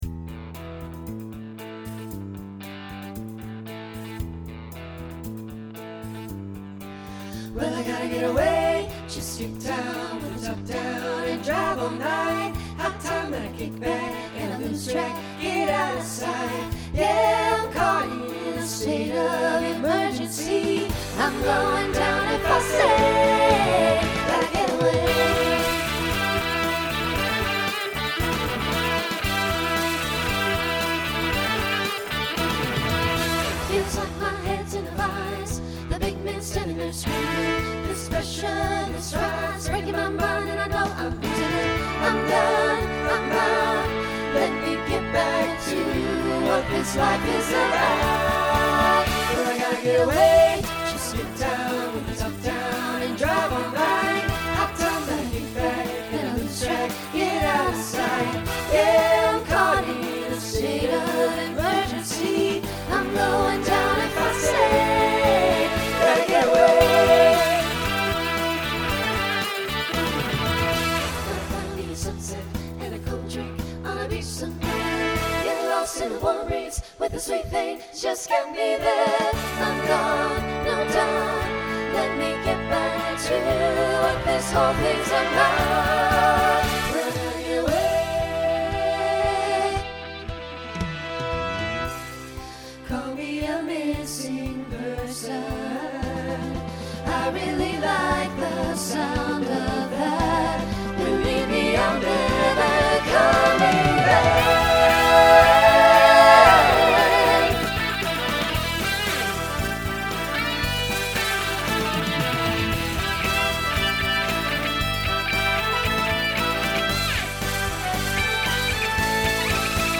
Voicing SATB Instrumental combo Genre Country , Rock